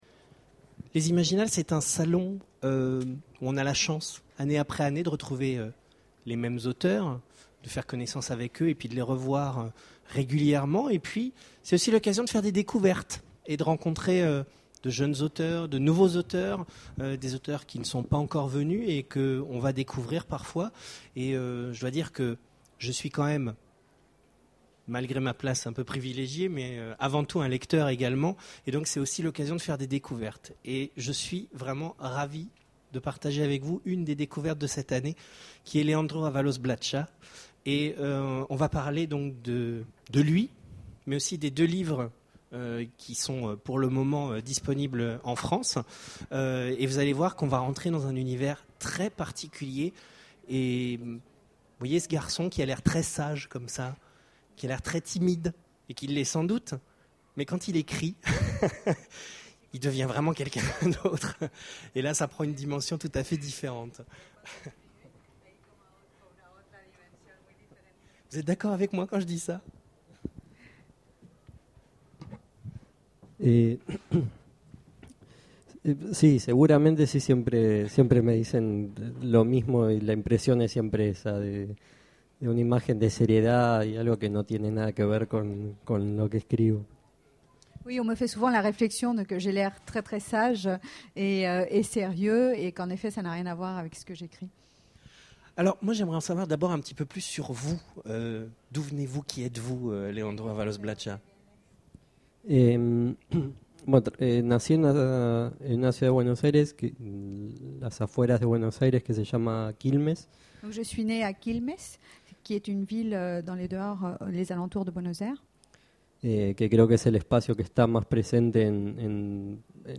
Imaginales 2014
Mots-clés Rencontre avec un auteur Conférence Partager cet article